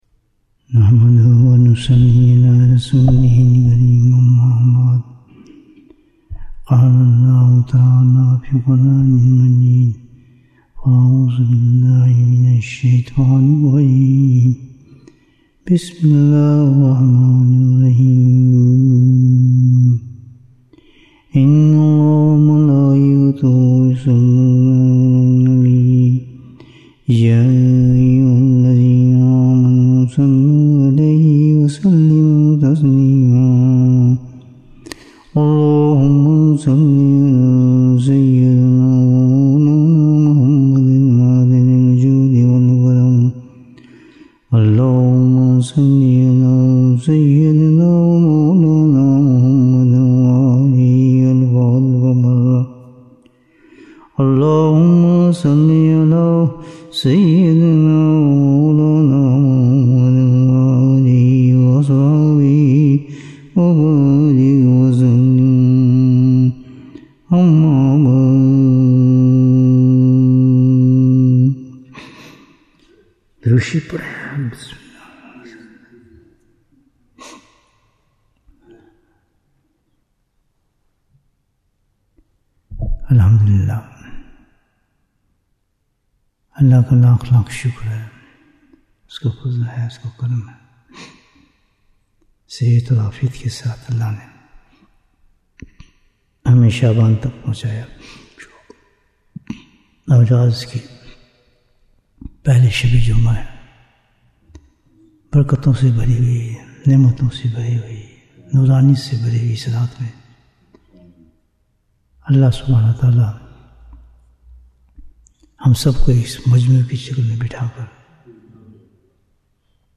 Bayan, 82 minutes 22nd January, 2026 Click for English Download Audio Comments What is Hidaayah?